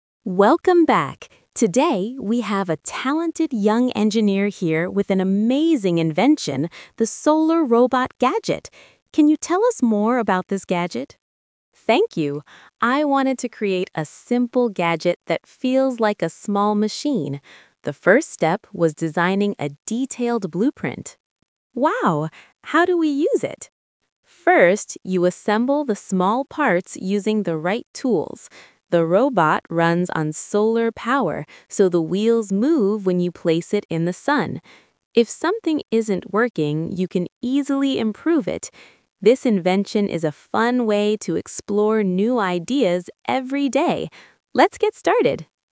Solar Robot Interview